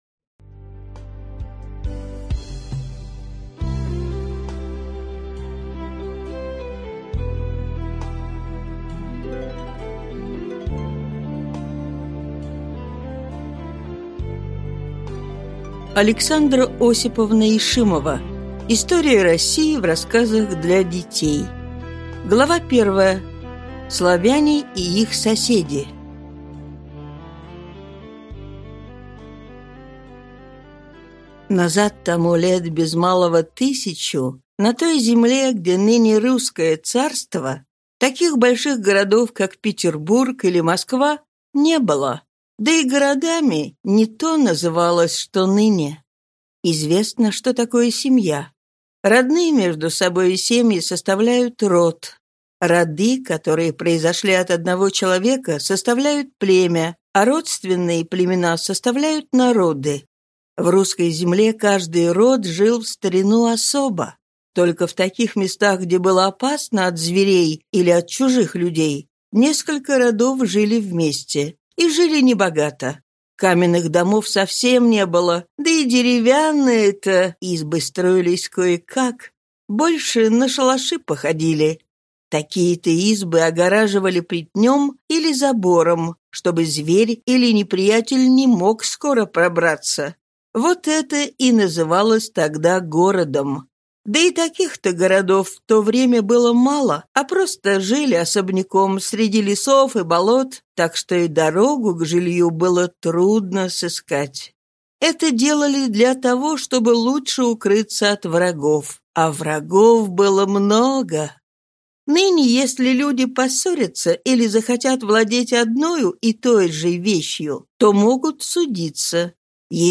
Аудиокнига История России в рассказах для детей | Библиотека аудиокниг
Прослушать и бесплатно скачать фрагмент аудиокниги